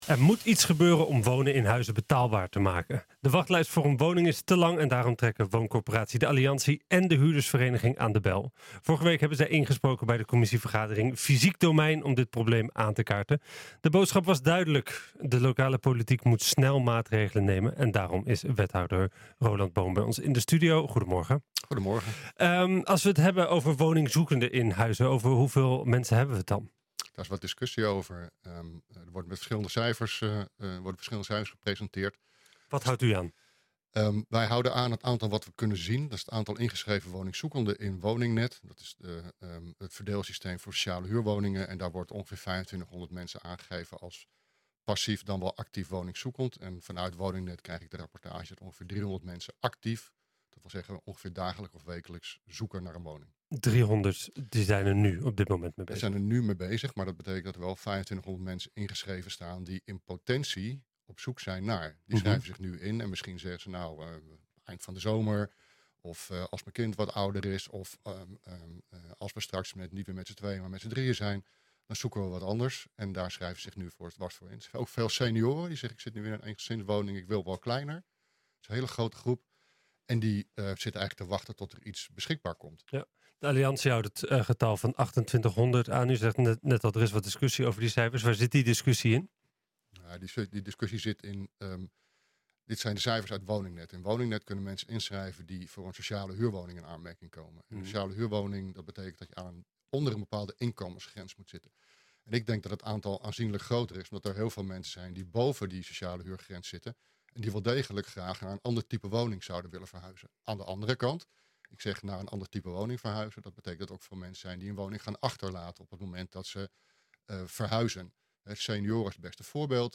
De boodschap was duidelijk: de lokale politiek moet snel maatregelen nemen. En daarom is wethouder Roland Boom bij ons in de studio.